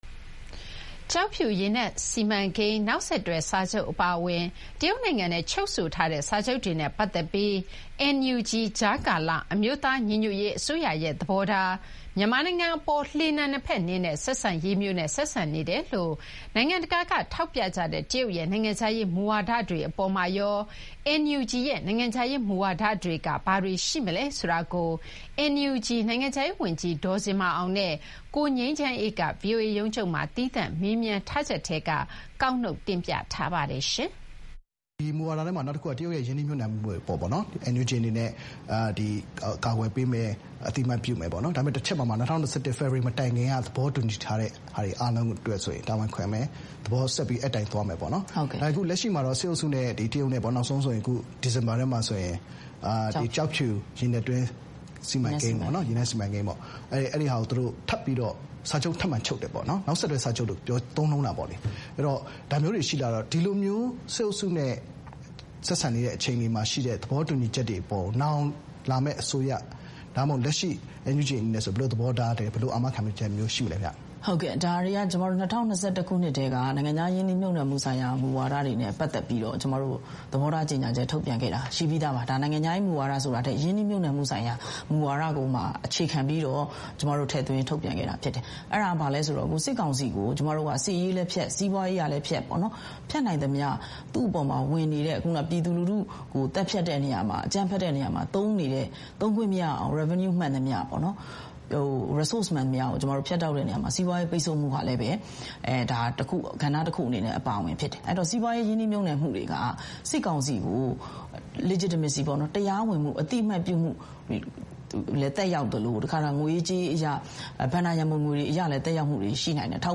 သီးသန့် မေးမြန်းထားပါတယ်။